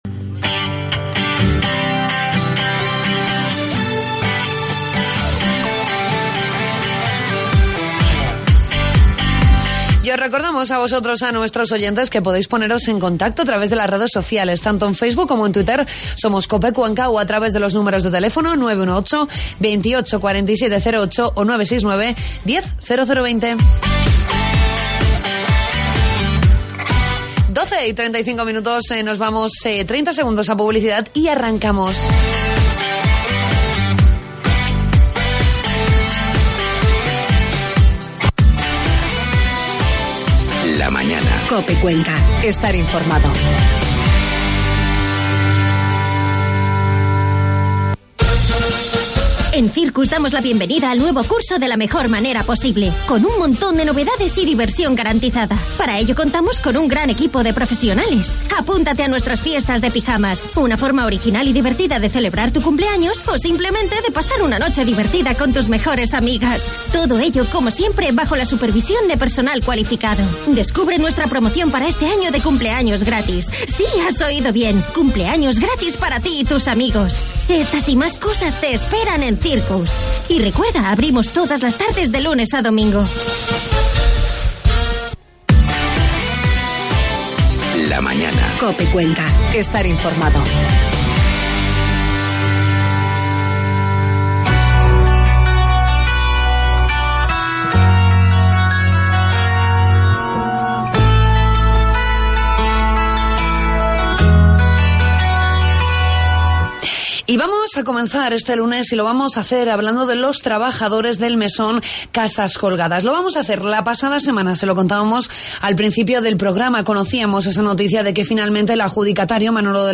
Entrevistamos a los extrabajadores del Mesón Casas Colgadas para conocer su situación. En nuestra sección 'Salud en Familia' con la Clínica Bueso hablamos de lactancia materna.